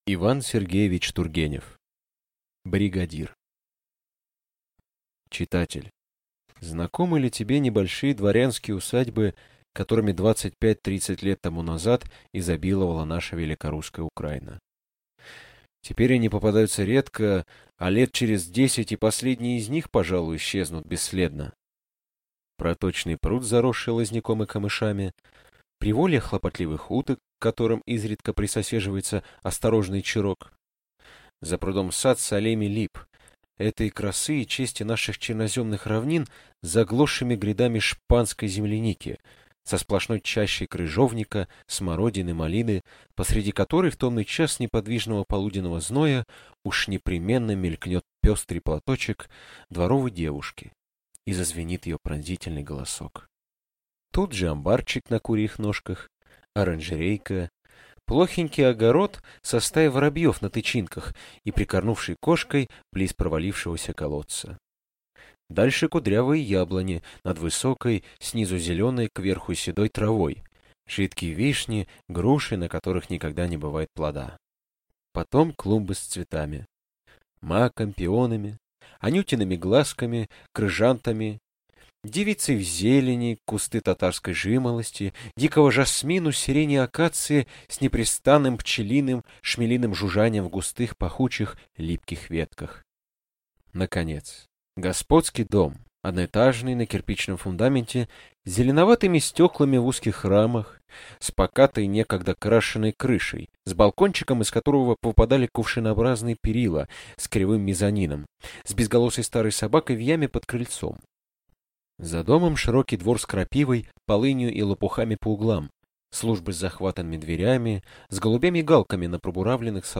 Аудиокнига Бригадир | Библиотека аудиокниг